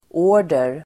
Uttal: ['å:r_der]